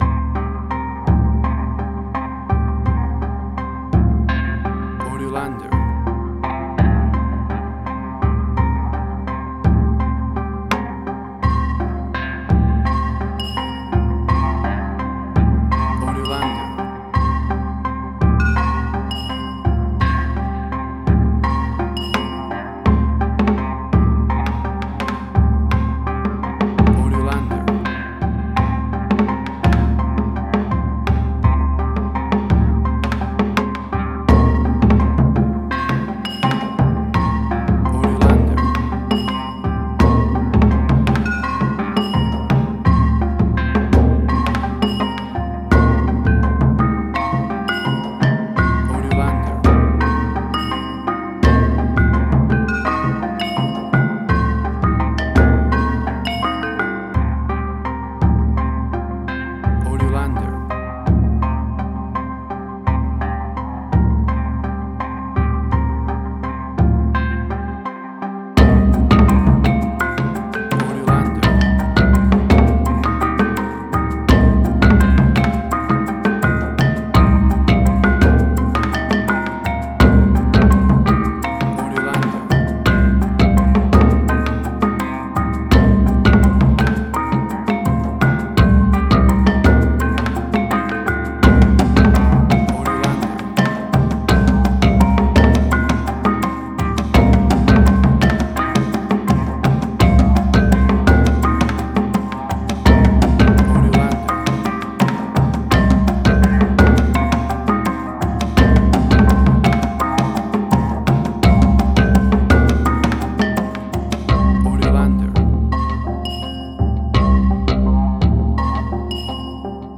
Asian Ambient.
Tempo (BPM): 84